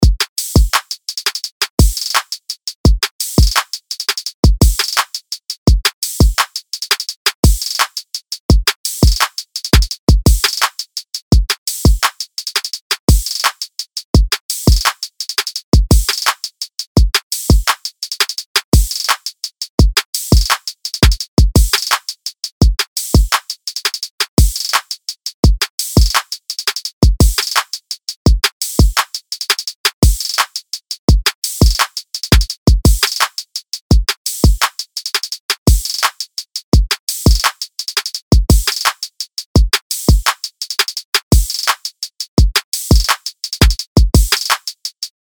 LP 188 – DRUM LOOP – HIPHOP – 160BPM